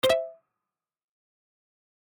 pause-continue-click.ogg